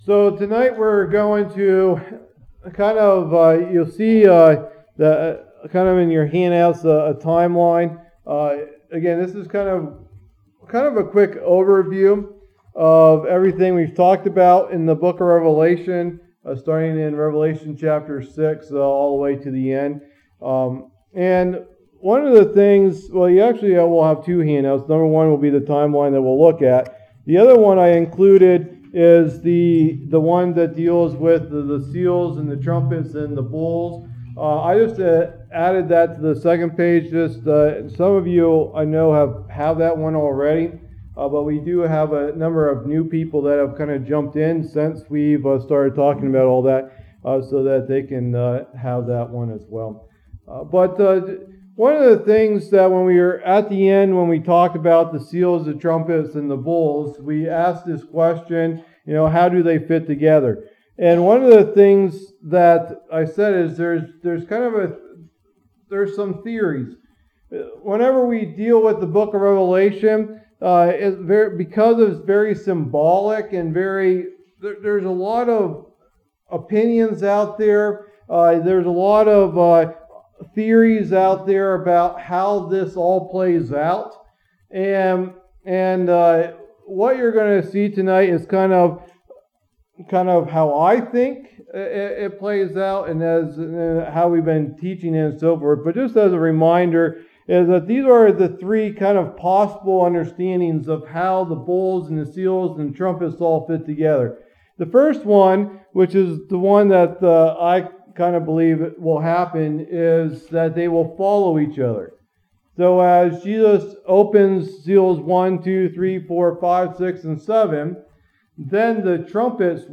Message #56 in the "Book of Revelation" teaching series